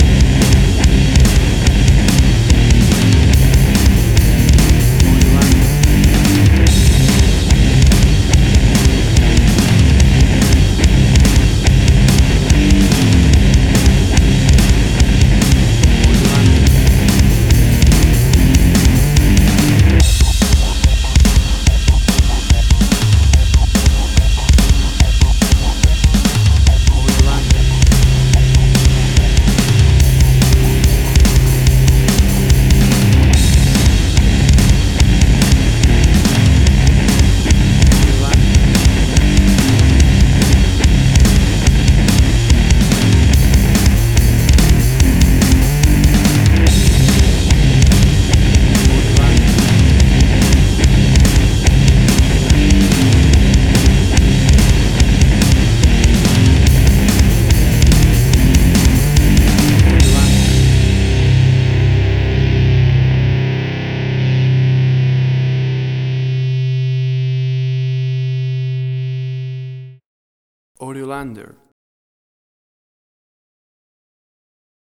An angry and scary piece of aggressive rock metal.
Tempo (BPM): 144